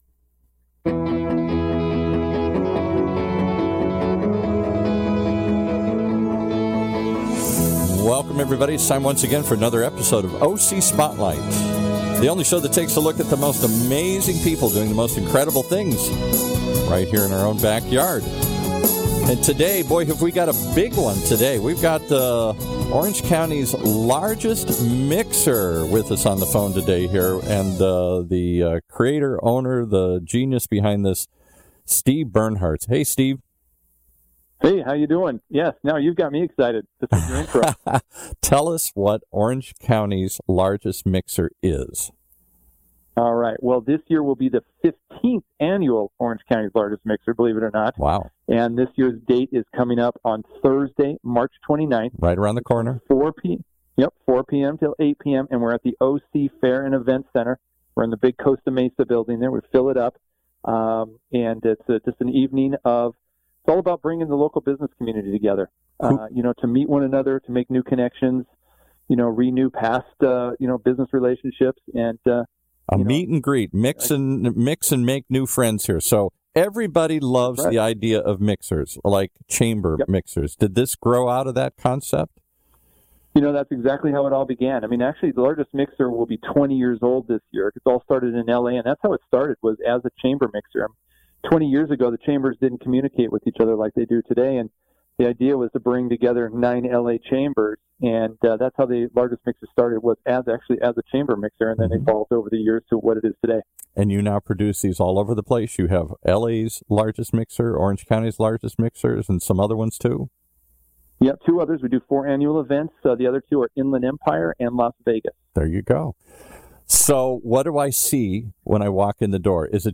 For those of you that couldn't make it to the OC Fairgrounds recently for OC's LARGEST MIXER, you missed one heck of a party. Thousands of people packed into a bldg at the Fairgrounds sampling local cuisine and connecting with the most amazing cross section of OC business owners imaginable including OC TALK RADIO (that was priviliged to be a media sponsor and streaming live from the event from 4-8pm.)